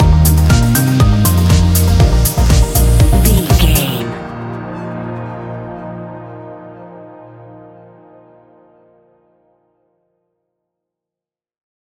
Ionian/Major
A♯
house
electro dance
synths
techno
trance
instrumentals